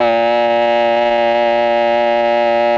RED.OBOE   4.wav